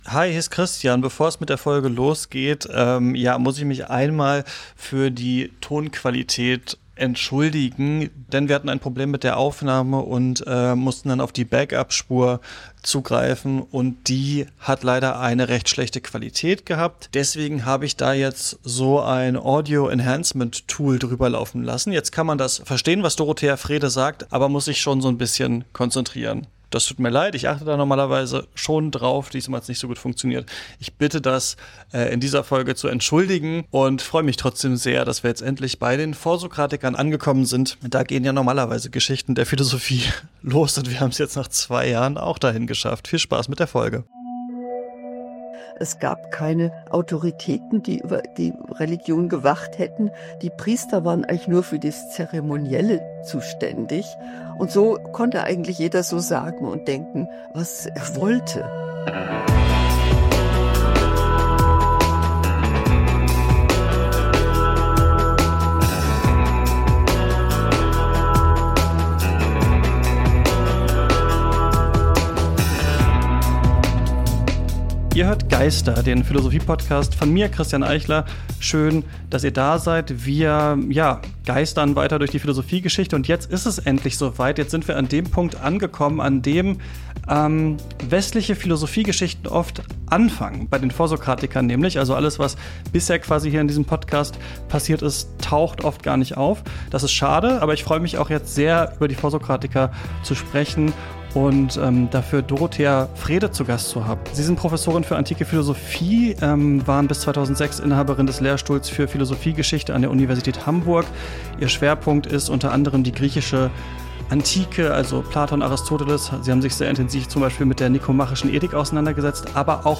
(Ich bitte die durchwachsene Audioqualität zu entschuldigen, es gab ein Problem mit der Aufnahme) Mehr